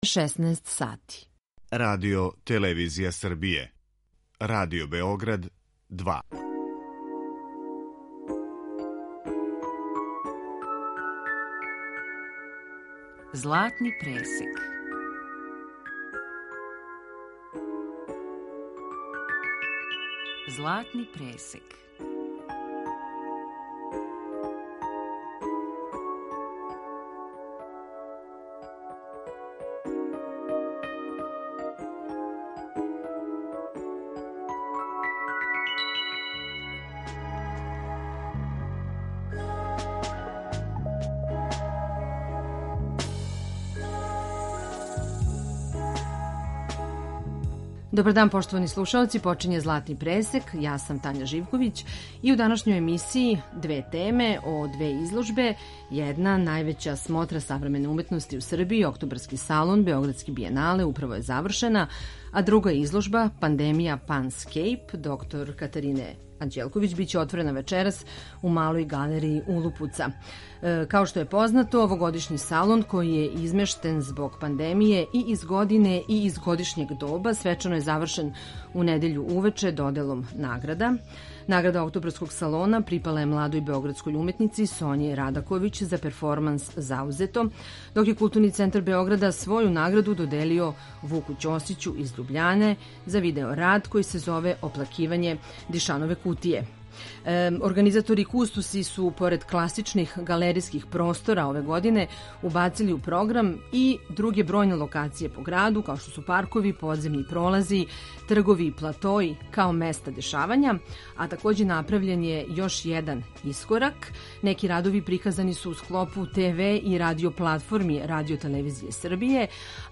У данашњој емисији кустоси Kултурног центра Београда рекапитулираће најпрестижнију смотру савремене ликовне и визуелне уметности у Србији.